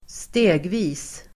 Uttal: [²st'e:gvi:s]